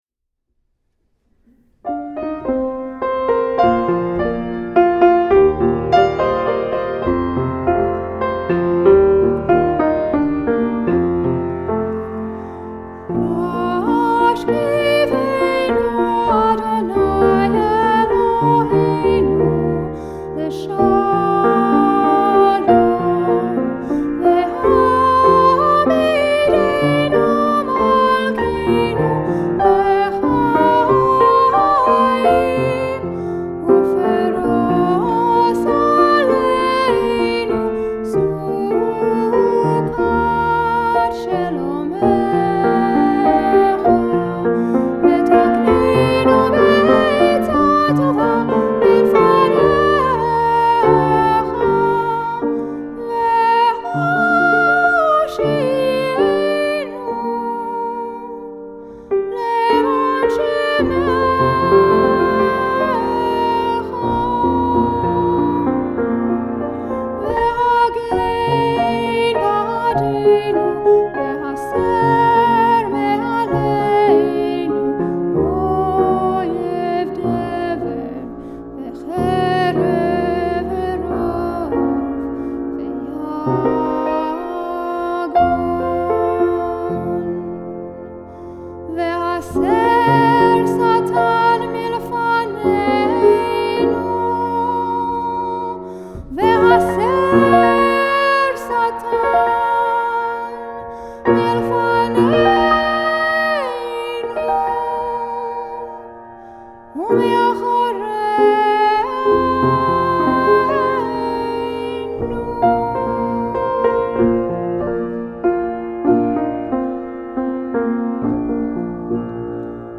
piano (recorded live